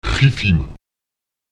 Lautsprecher qicim [ÈxiTim] erhellen, erleuchten (hell machen)